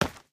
main Divergent / mods / Footsies / gamedata / sounds / material / human / step / asphalt02gr.ogg 5.6 KiB (Stored with Git LFS) Raw Permalink History
asphalt02gr.ogg